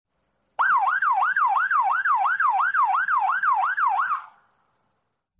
Police Siren Sound Effect Download: Instant Soundboard Button
Police Siren Sound Button - Free Download & Play